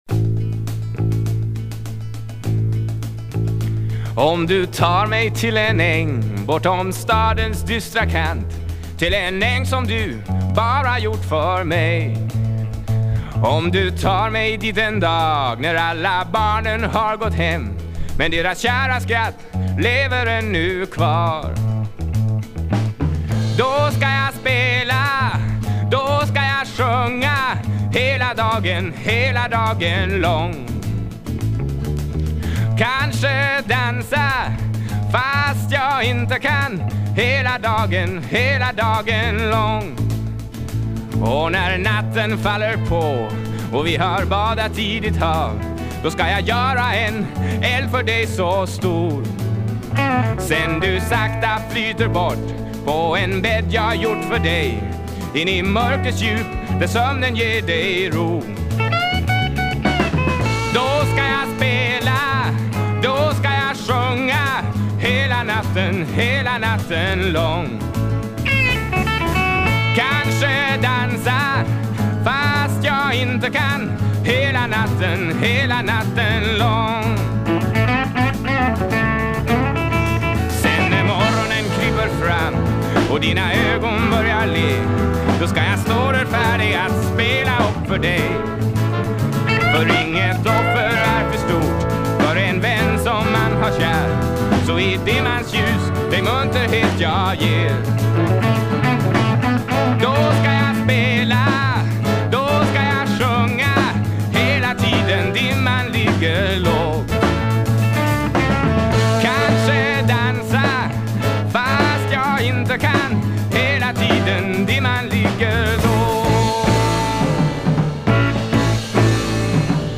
1. 70'S ROCK >
SSW / FOLK# SOFT ROCK# FREE SOUL# FRENCH